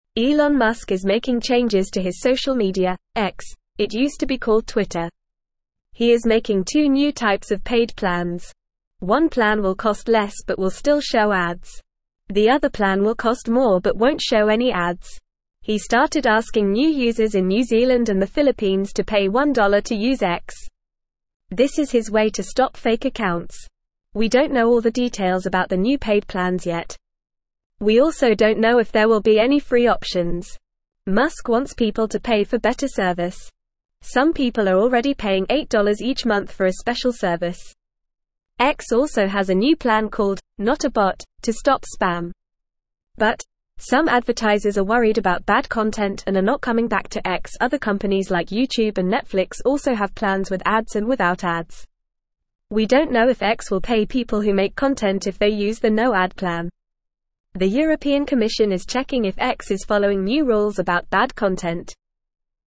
Fast
English-Newsroom-Beginner-FAST-Reading-Elon-Musks-Social-Media-X-Introduces-Paid-Plans.mp3